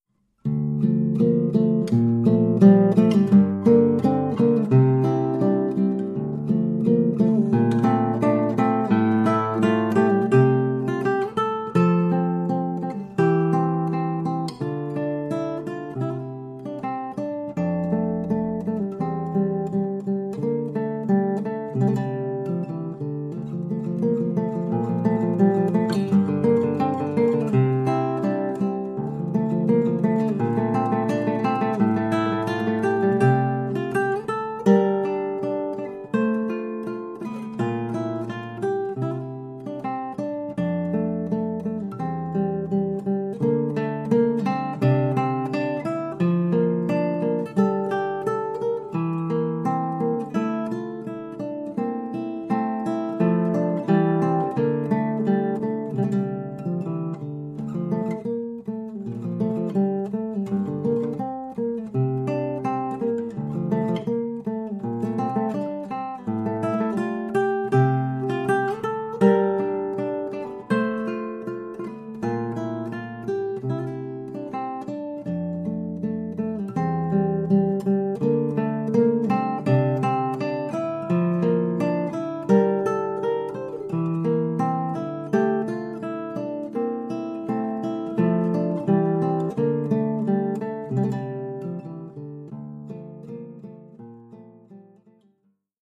Formule instrumentale : Guitare solo
Oeuvre pour guitare solo.